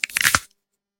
Bone Crunch
A sickening bone crunch with splintering crack and muffled tissue compression
bone-crunch.mp3